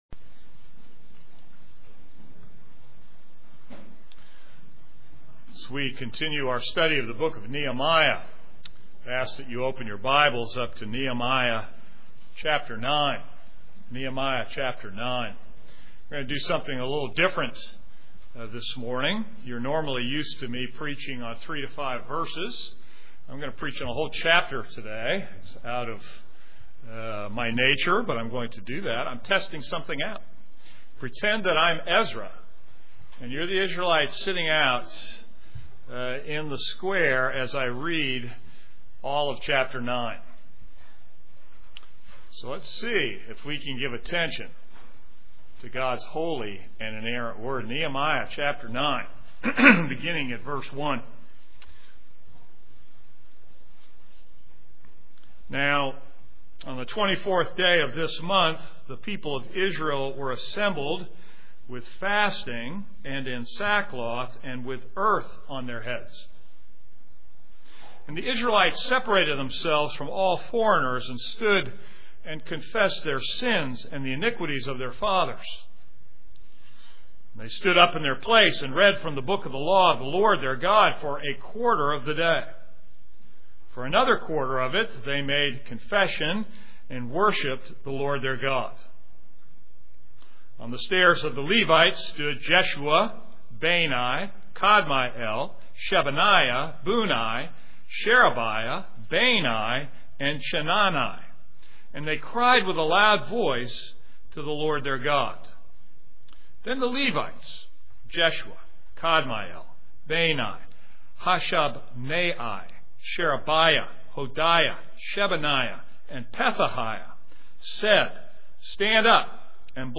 This is a sermon on Nehemiah 9.